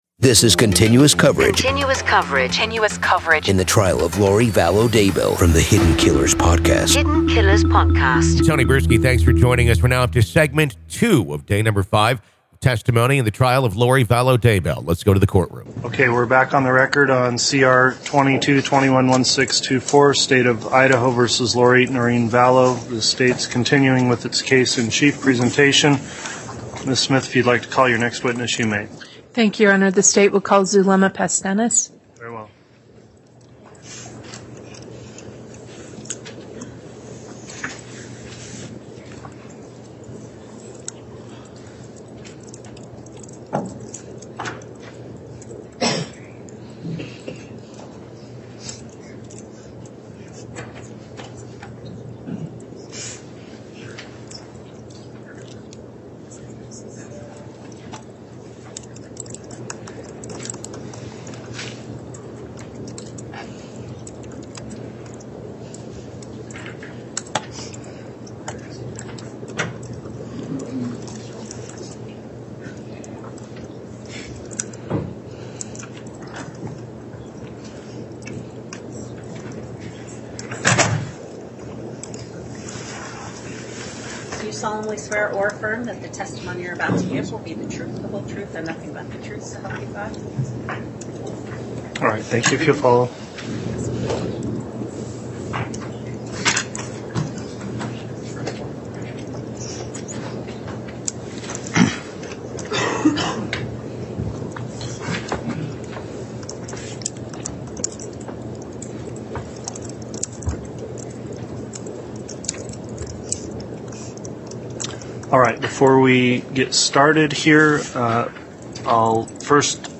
The Trial Of Lori Vallow Daybell Day 5 Part 2 | Raw Courtroom Audio